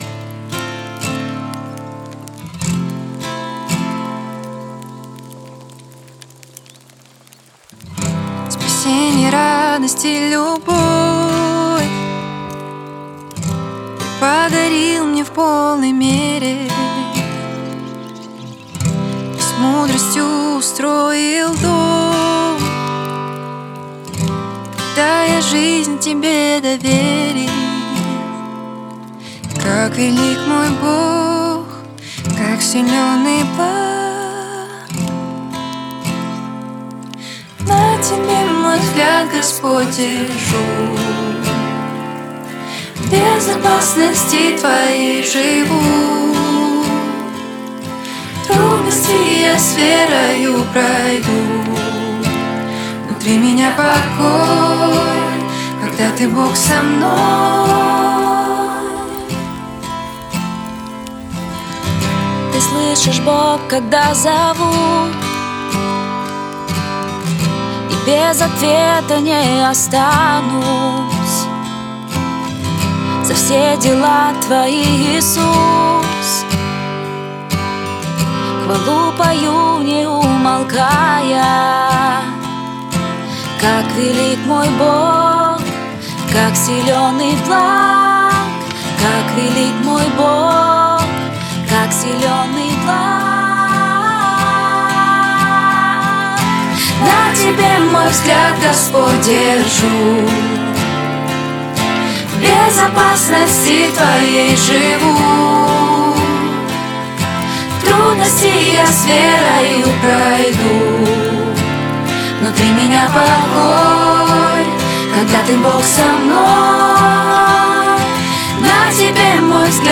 615 просмотров 736 прослушиваний 62 скачивания BPM: 88